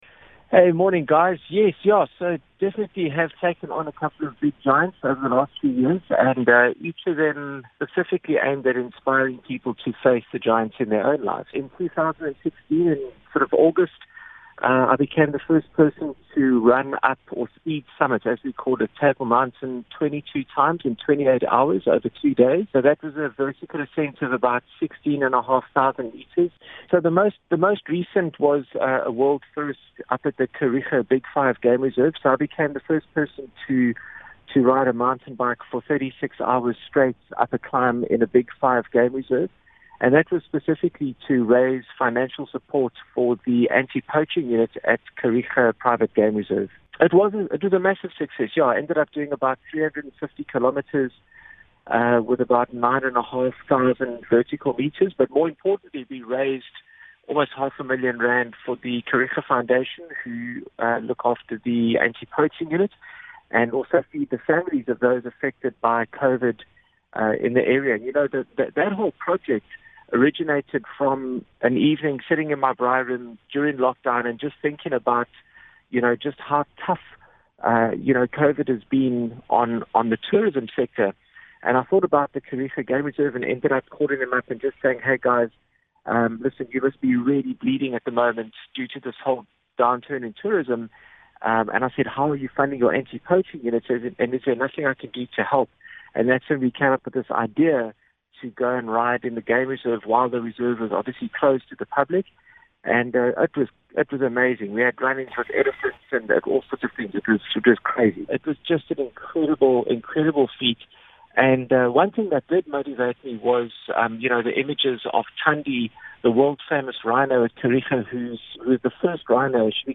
He spoke to the Smile Breakfast team about some of his other record breaking achievements and future plans.